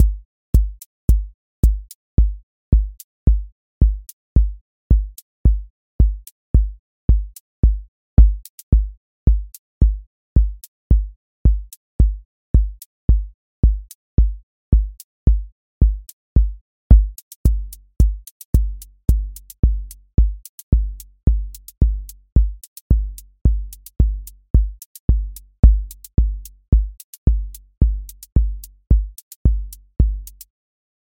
house
voice_kick_808 voice_hat_rimshot voice_sub_pulse